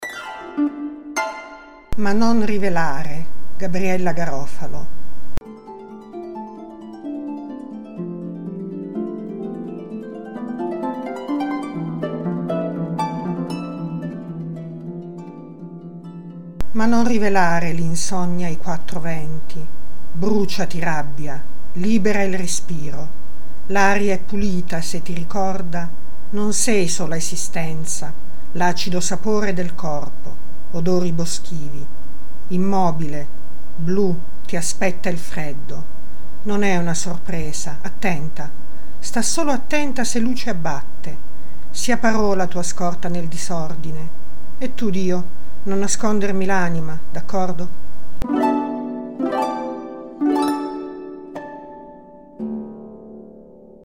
Suoni poetici » Poesie recitate da artisti